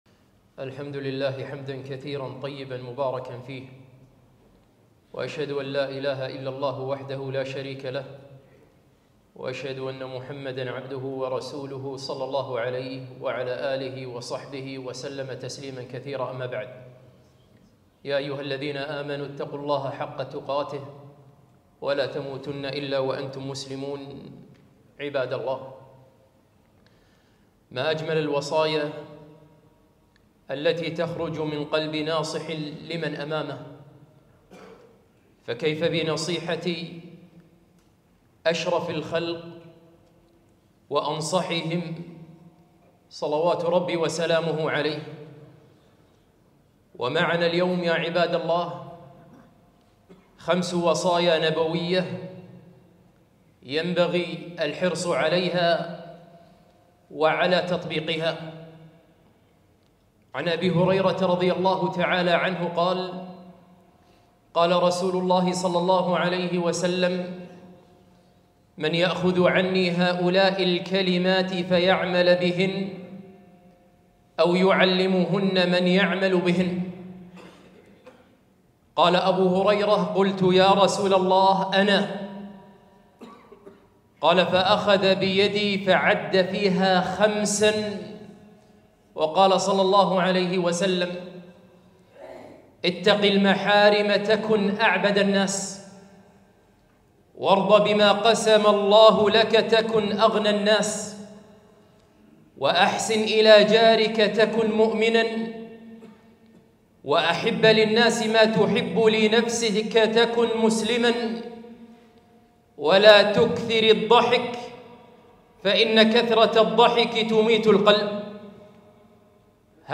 خطبة - الوصايا الخمس